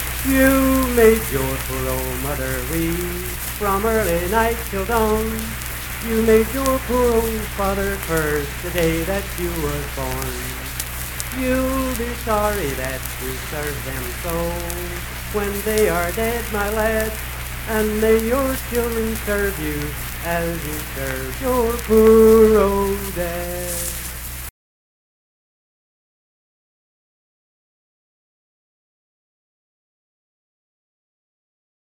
Unaccompanied vocal music
Verse-refrain 11(8).
Voice (sung)